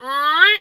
pgs/Assets/Audio/Animal_Impersonations/duck_2_quack_01.wav at master
duck_2_quack_01.wav